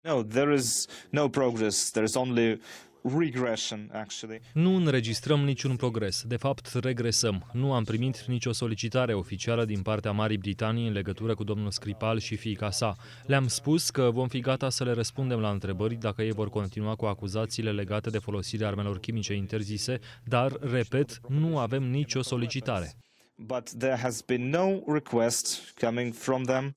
Într-o conferința de presă transmisă de Russia Today, Lavrov a declarat că așteaptă o cerere oficială de infirmații din partea Guvernului Britanic.
14mar-14-Lavrov-tradus-despre-spionul-rus-si-fiica-otraviti.mp3